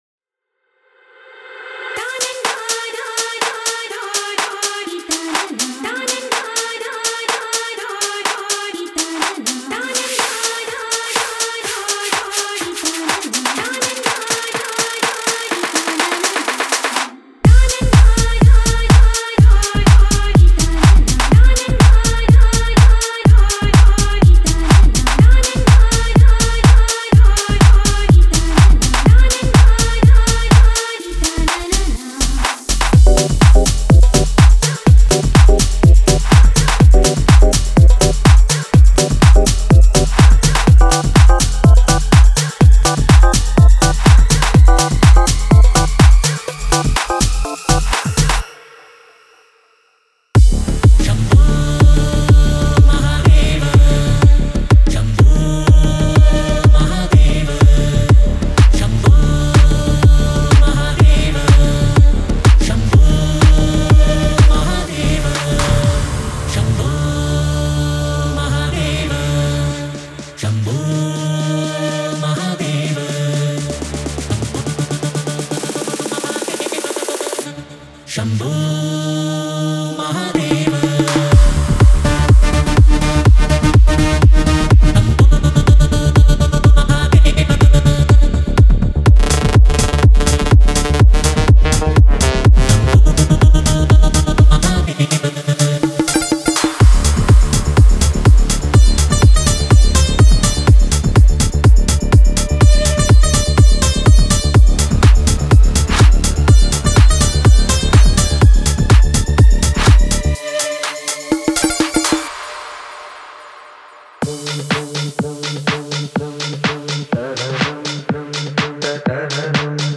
Genre:House
デモサウンドはコチラ↓